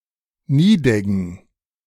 Nideggen (German: [ˈniːdɛɡn̩]
De-Nideggen.ogg.mp3